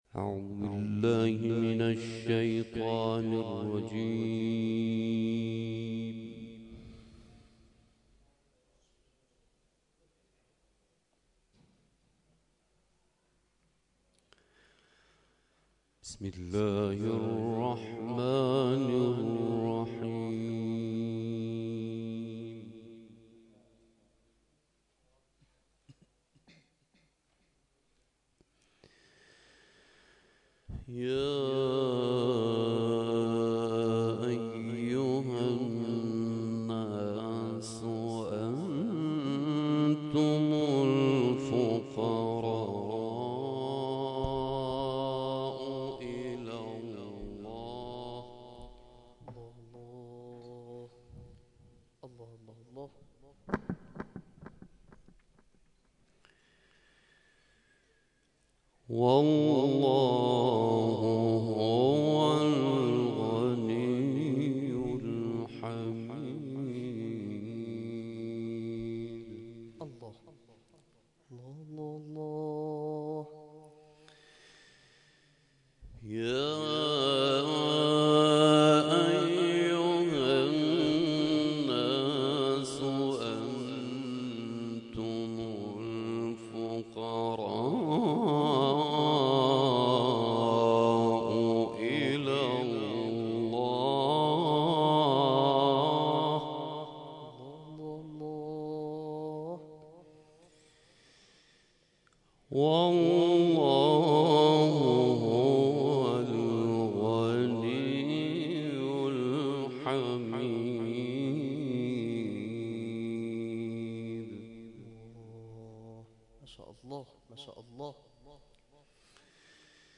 برپایی کرسی تلاوت فجر تا امنیت پرواز در دهه پایانی سال96+صوت و عکس